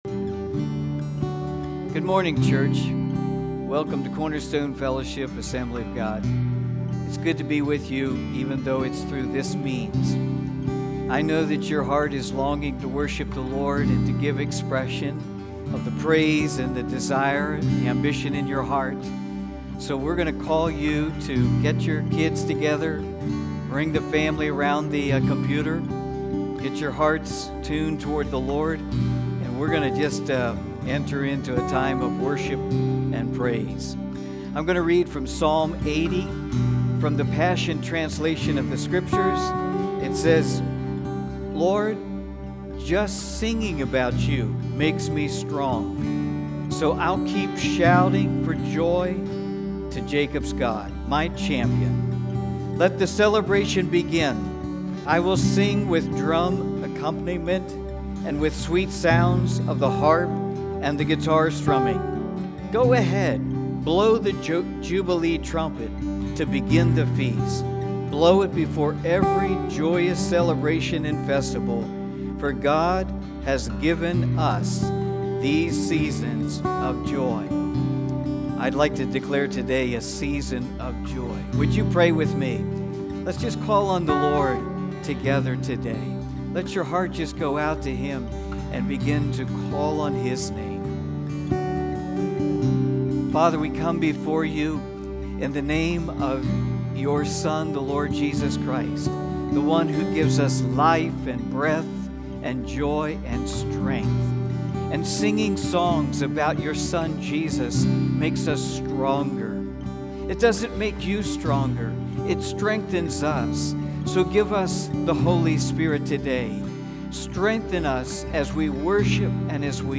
Cornerstone Fellowship Sunday morning online service, May 17, 2020. Call to Worship, Worship and Praise, Proverbs 4:23. Americans are at a crossroads; two competing world views are conflicting with each other.